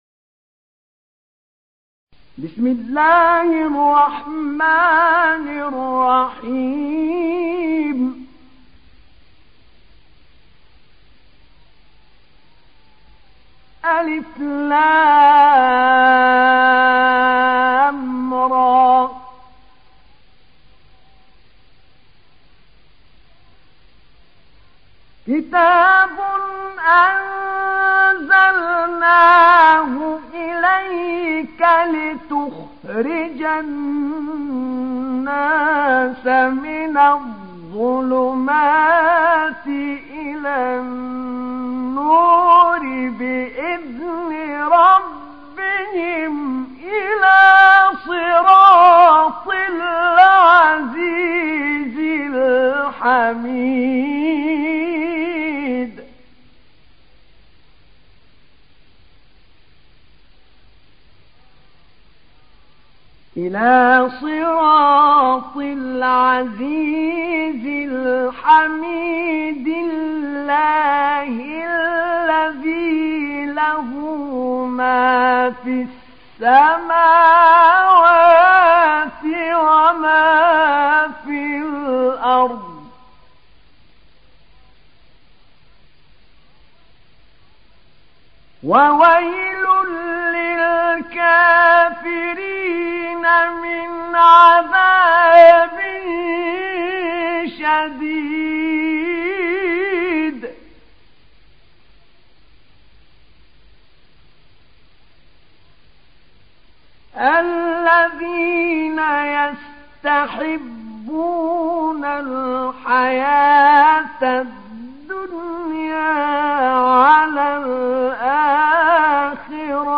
تحميل سورة إبراهيم mp3 بصوت أحمد نعينع برواية حفص عن عاصم, تحميل استماع القرآن الكريم على الجوال mp3 كاملا بروابط مباشرة وسريعة